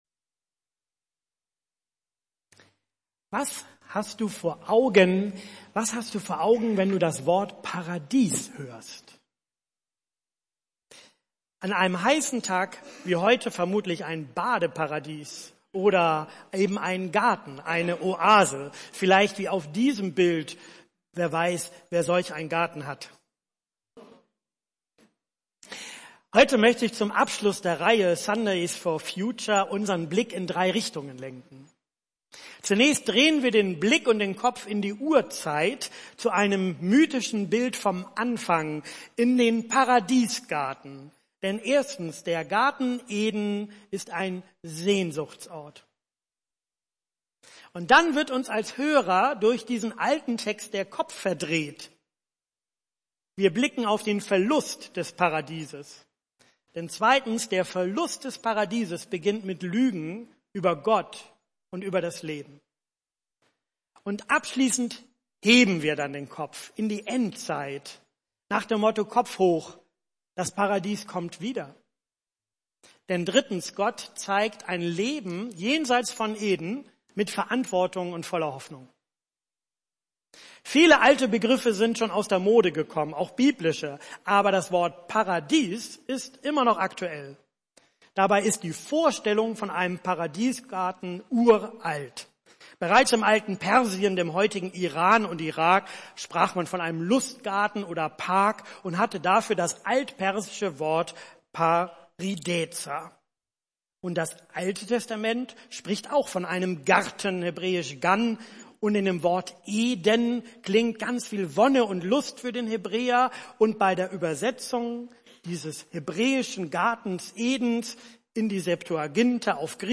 Predigttext: Genesis 2, 8-18; Genesis 3